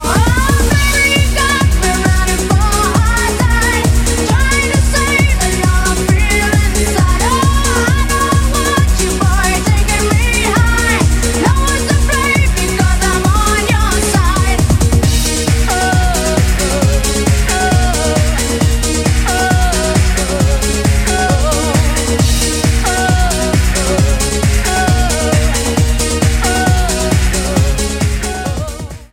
евродэнс